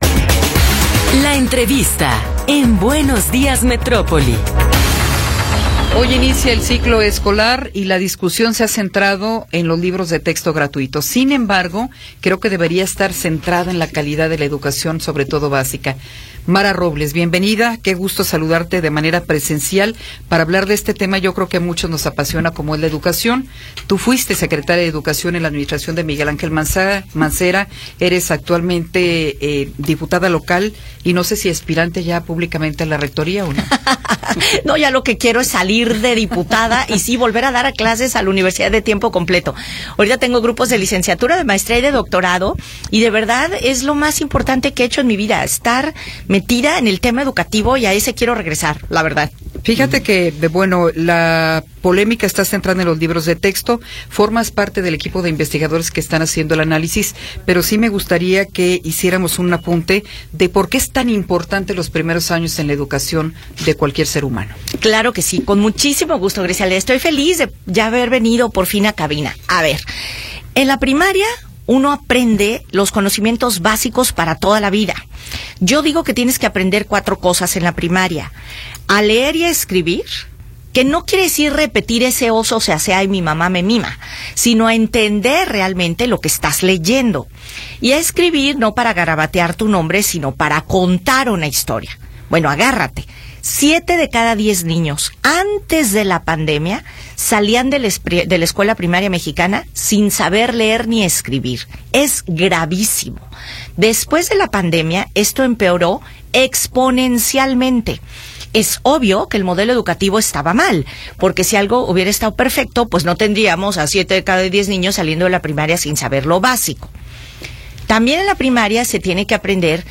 Entrevista con Mara Robles Villaseñor